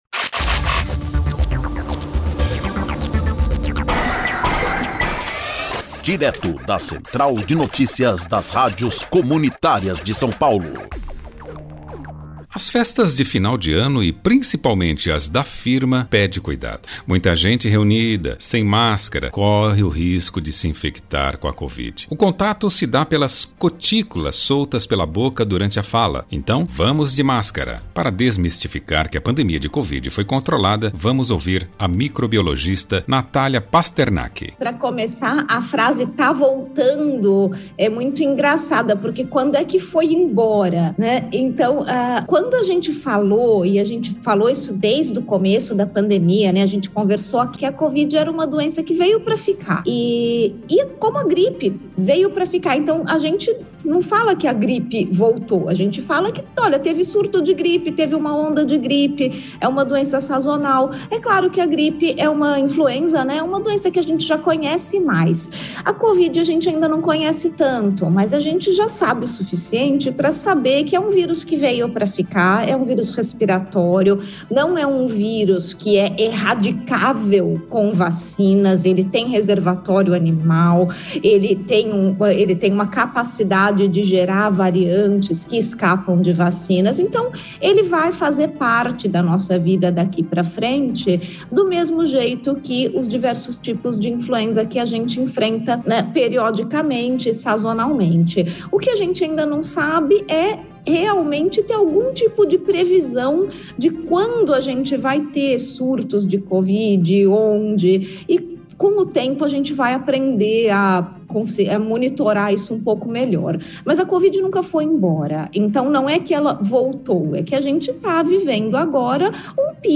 Para desmistificar que a epidemia de covid foi controlada vamos ouvir a microbiologistas Natalia Pasternak.